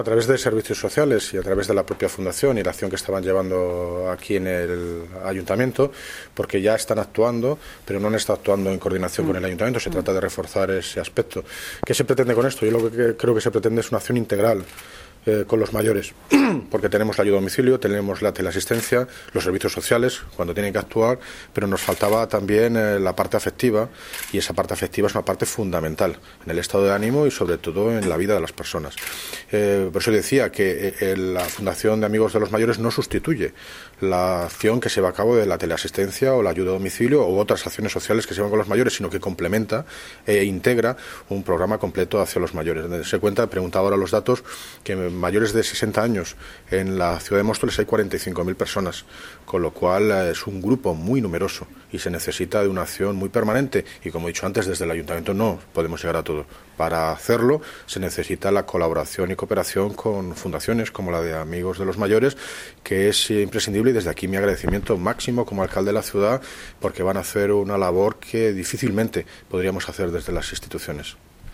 Audio - David Lucas (Alcalde de Móstoles) Sobre Convenio Amigos Mayores